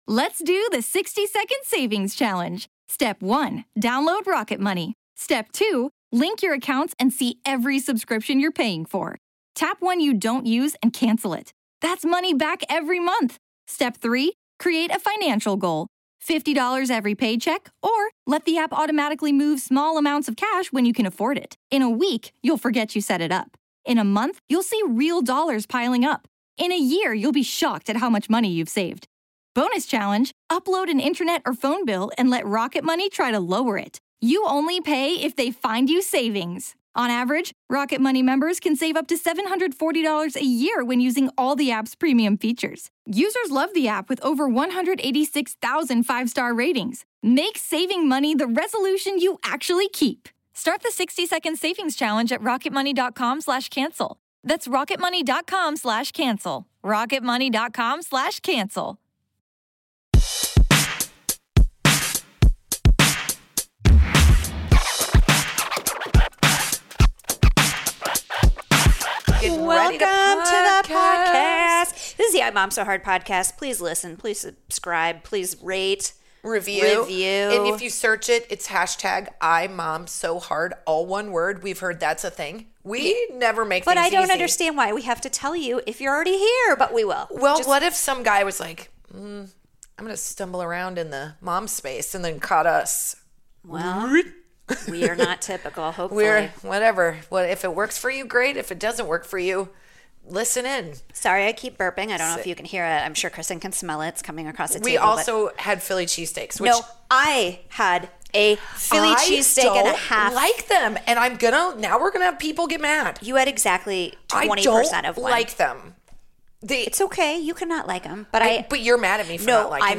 This hilarious comedy podcast about motherhood is for moms by moms talking all about being a mom.